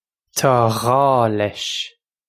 Listen to the pronunciation here. This comes straight from our Bitesize Irish online course of Bitesize lessons.